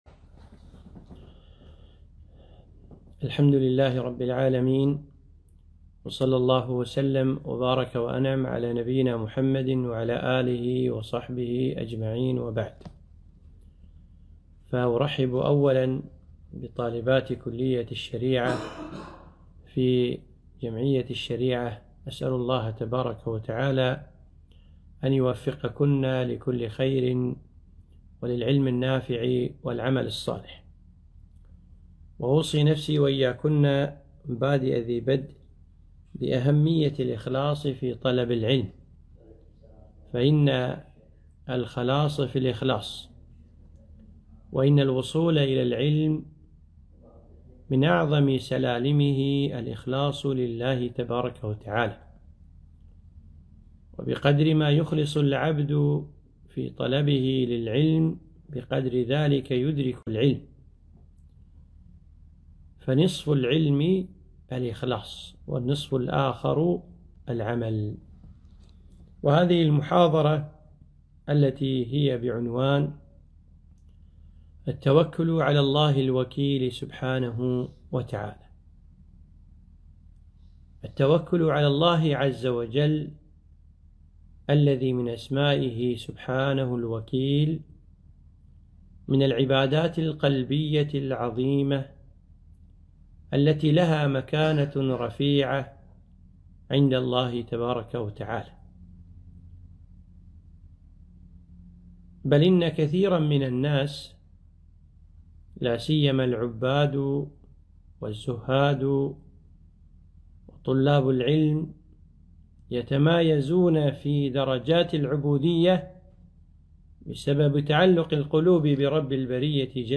محاضرة - التوكل على الله